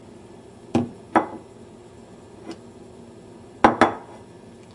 放下碗
描述：将碗放在桌子上
Tag: 噪音